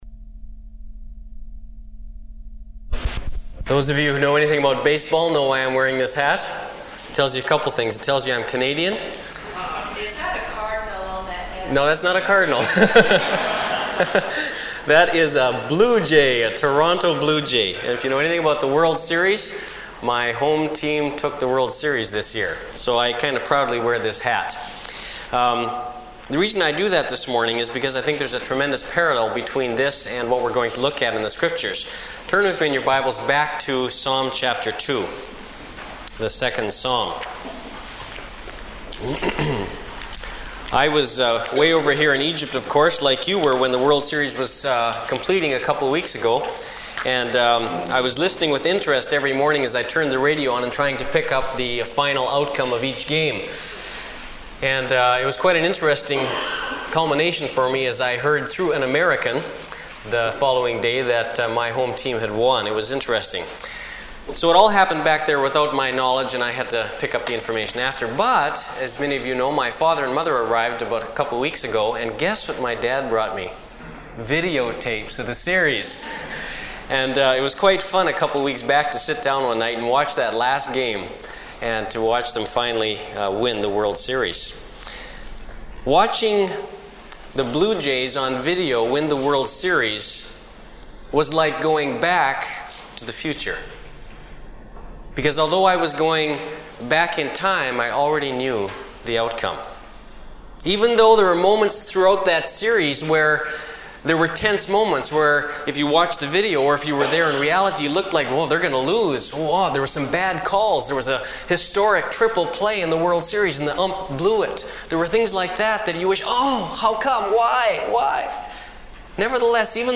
Preacher